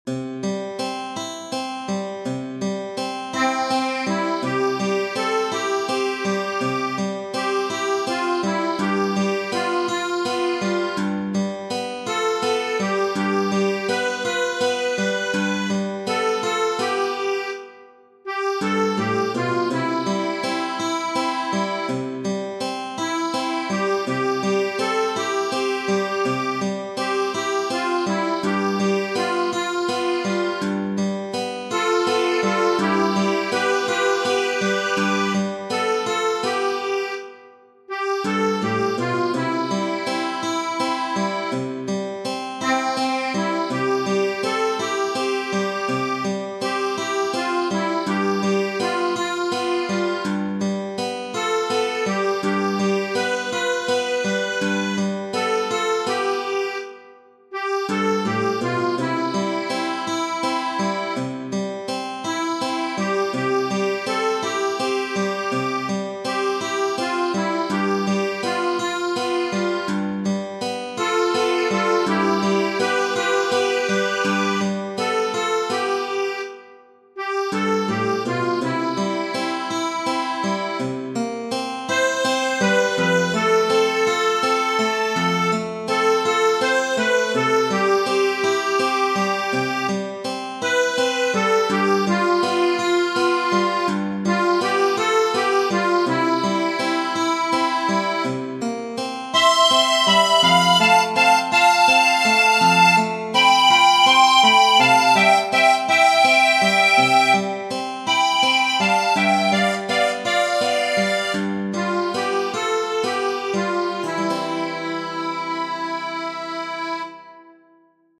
Tradizionale Genere: Sociali e Patriottiche Testo di anonimo E da Genova in Sirio partivano per l’America varcare, varcare i confin e da bordo cantar si sentivano tutti allegri del suo, del suo destin.